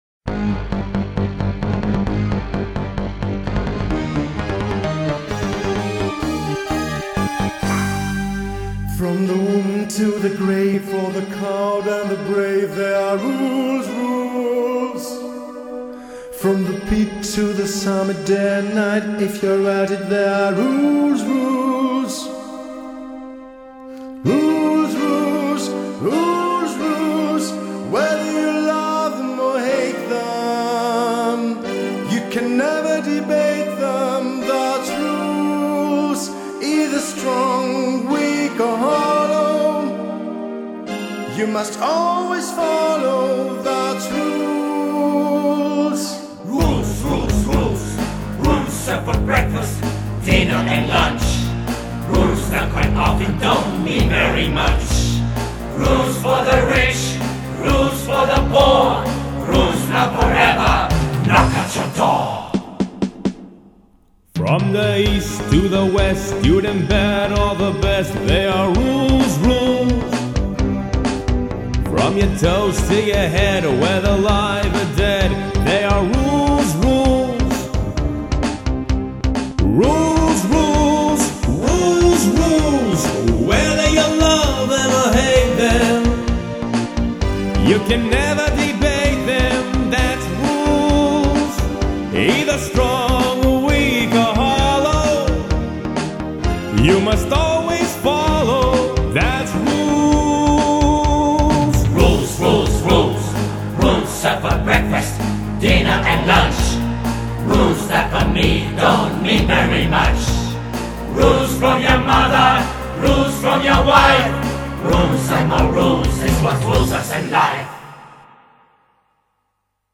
A musical